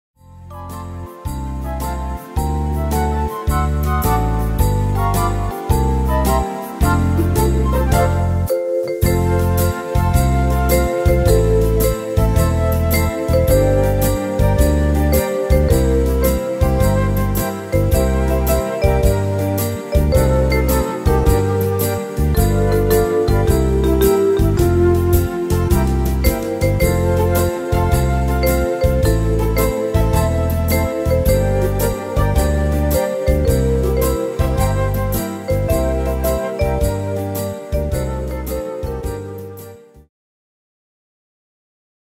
Tempo: 108 / Tonart: F-Dur